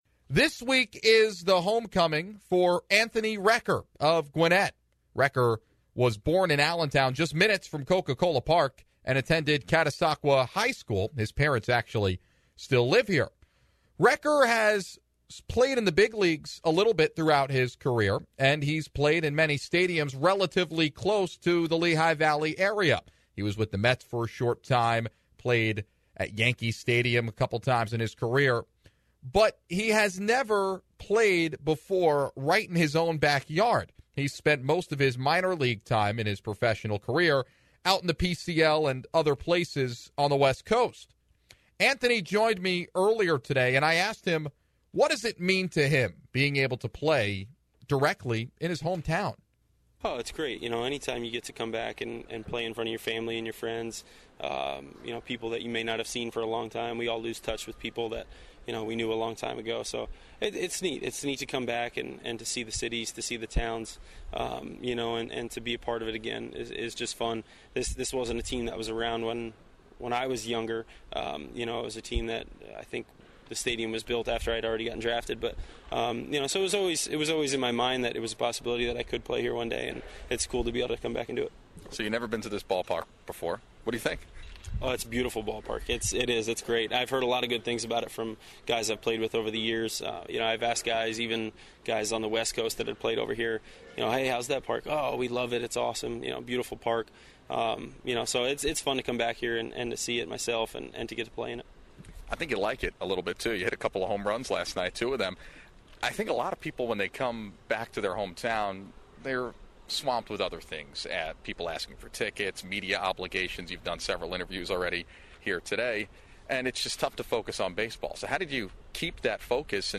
INTERVIEW with Braves C Anthony Recker